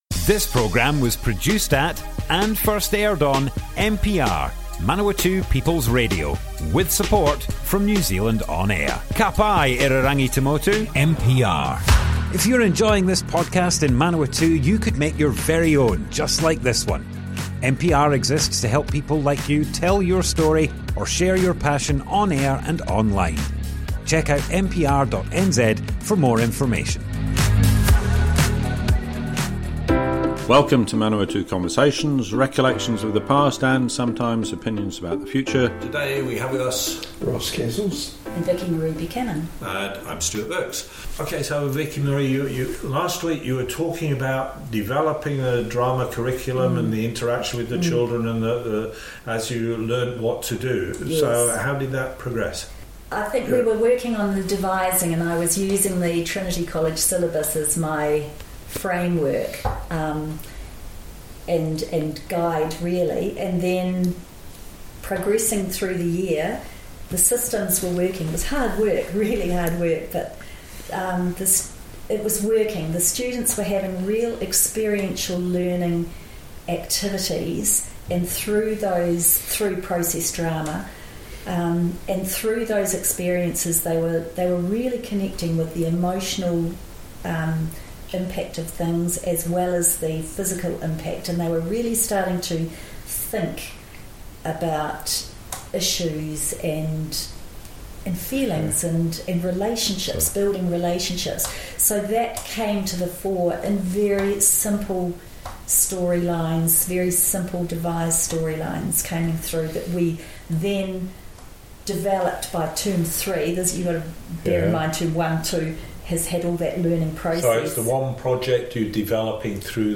Manawatu Conversations More Info → Description Broadcast on Manawatu People's Radio, 2nd July 2024.
oral history